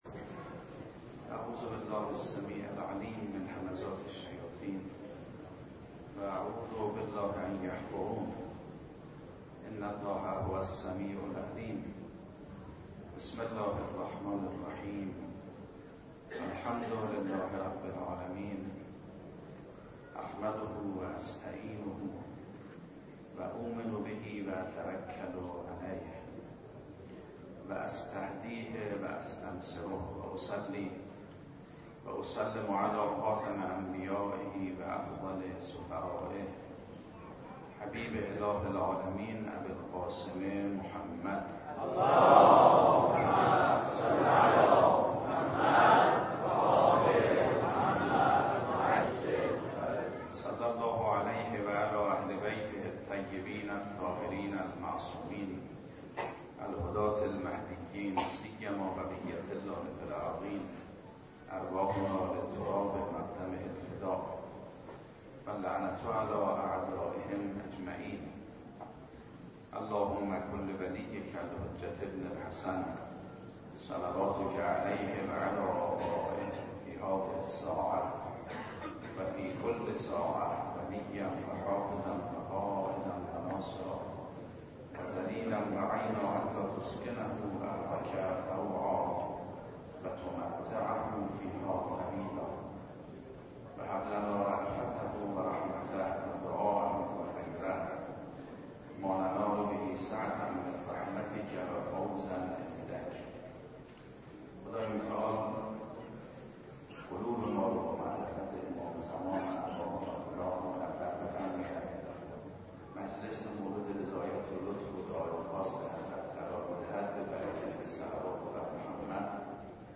سخنرانی آیت الله میرباقری- هیئت ثارالله (12).mp3